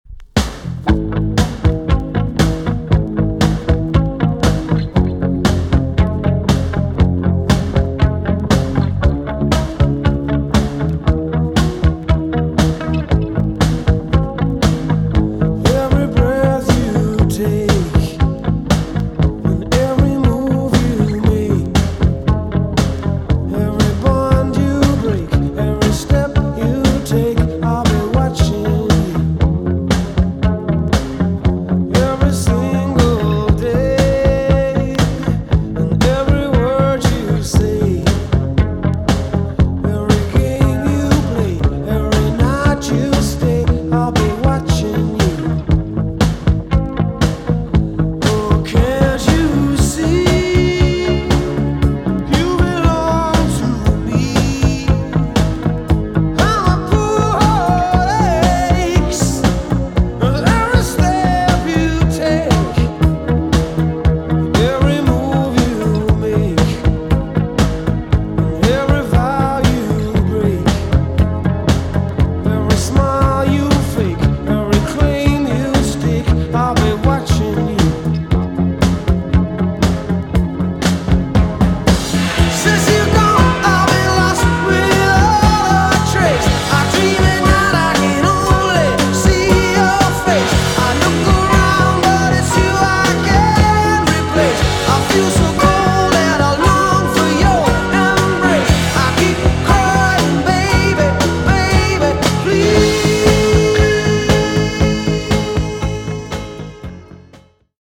EX 音はキレイです。
1983 , イギリスのロックバンド。